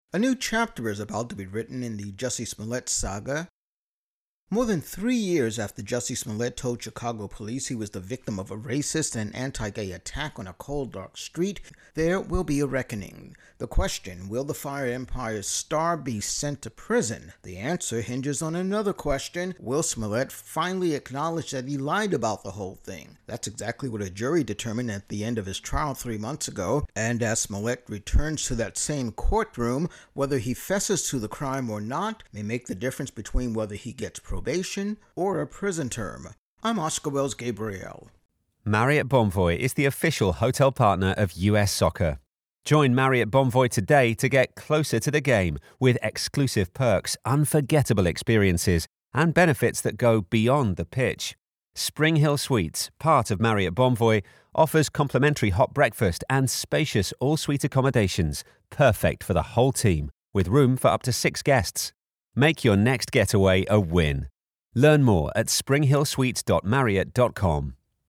Intro+voicer on Jussie Smollett sentencing